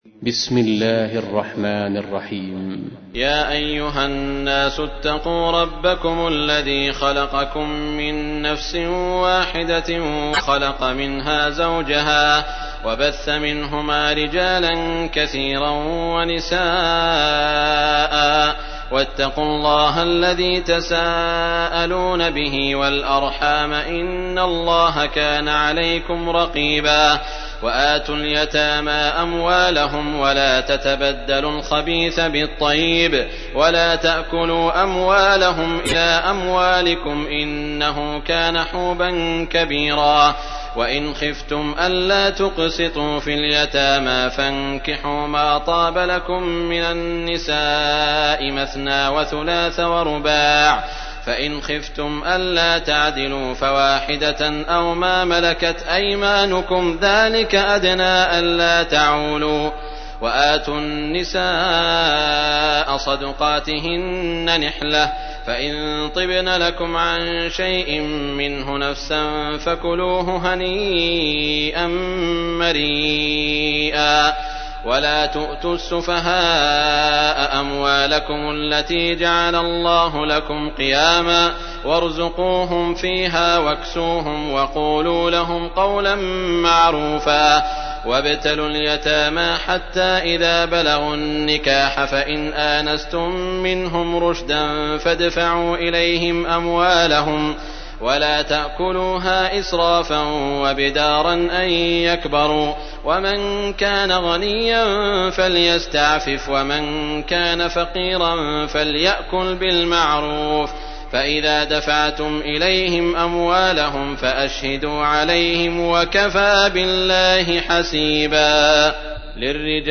تحميل : 4. سورة النساء / القارئ سعود الشريم / القرآن الكريم / موقع يا حسين